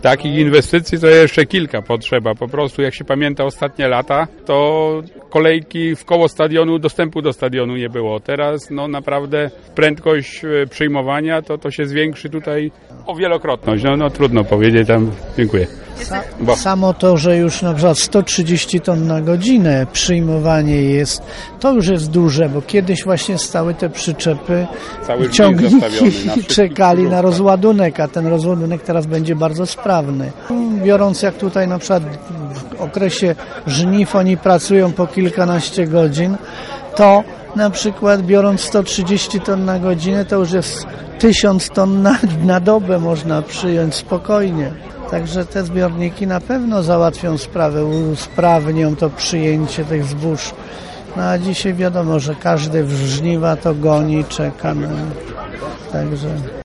Nowoczesny obiekt cieszy zarówno właścicieli jak i rolników, którzy licznie pojawili się na wczorajszym otwarciu. Nowy punkt przyjęć to prezent dla producentów zbóż na zbliżający się sezon żniwny, który ma usprawnić ich prace.